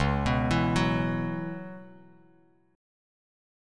Dbm11 chord